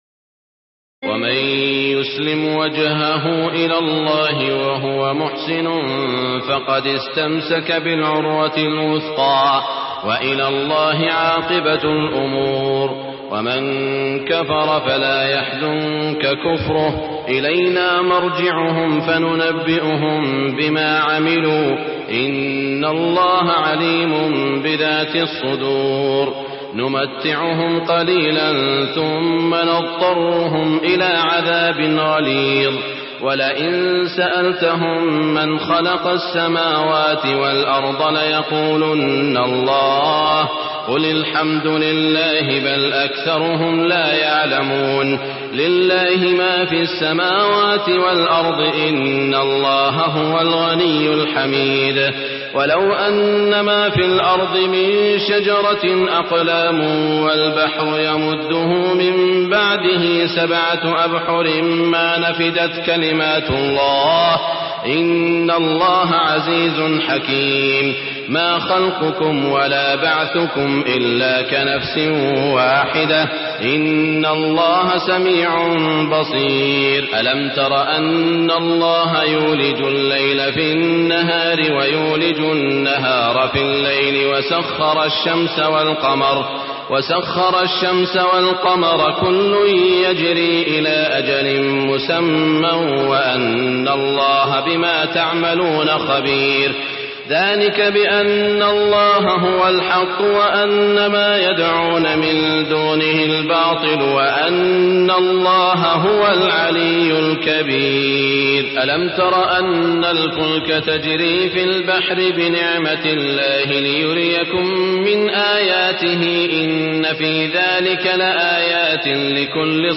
تراويح الليلة العشرون رمضان 1423هـ من سور لقمان (22-34) والسجدة و الأحزاب(1-34) Taraweeh 20 st night Ramadan 1423H from Surah Luqman and As-Sajda and Al-Ahzaab > تراويح الحرم المكي عام 1423 🕋 > التراويح - تلاوات الحرمين